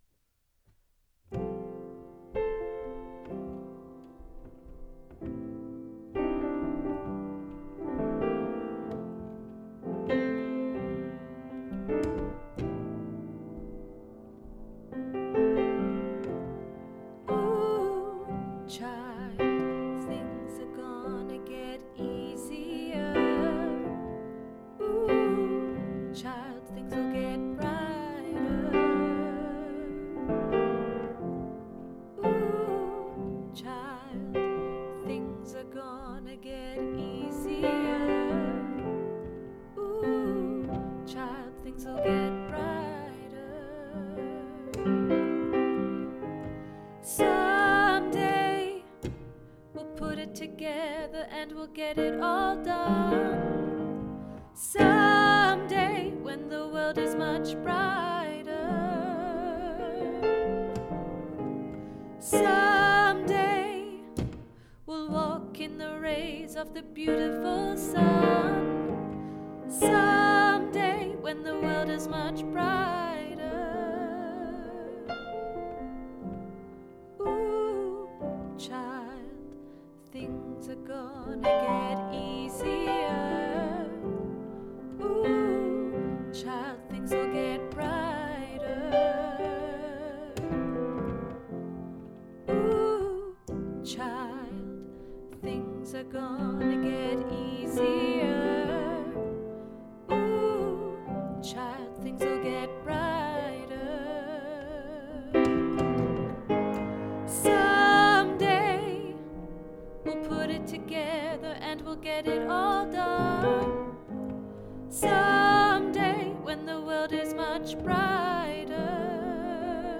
Ooh Child Alto
Ooh-Child-Alto.mp3